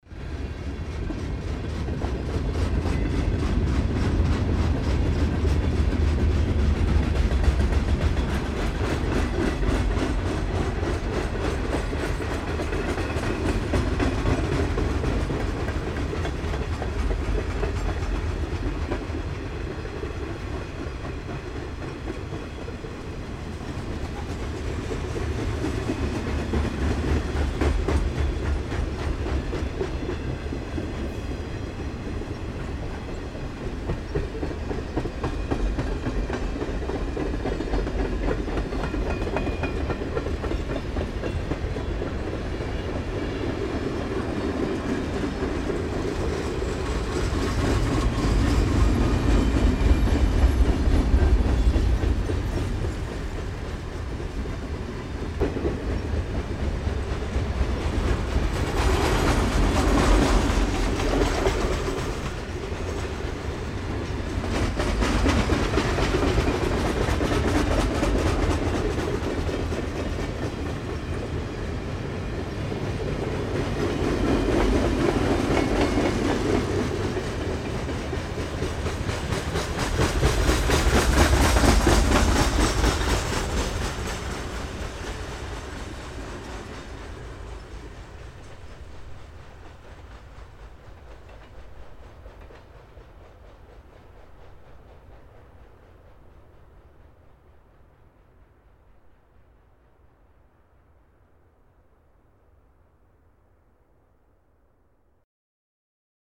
Звуки поезда